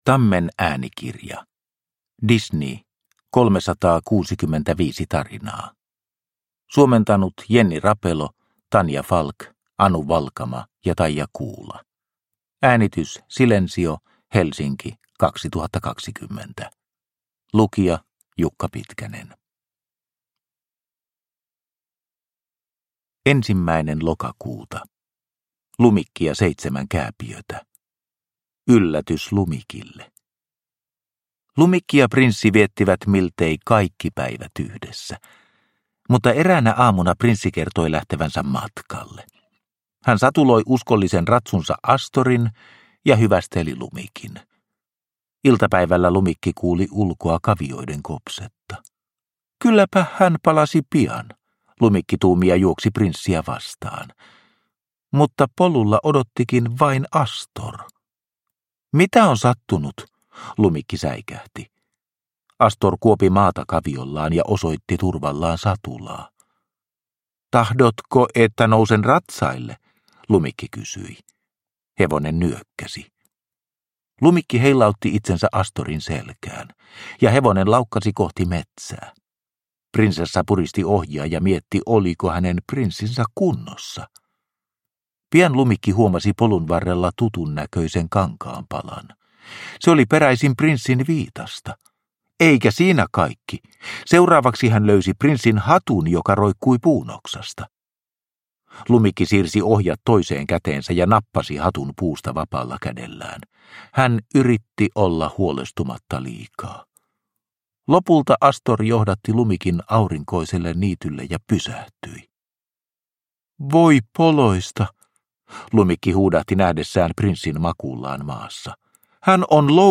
Disney 365 tarinaa, Lokakuu – Ljudbok – Laddas ner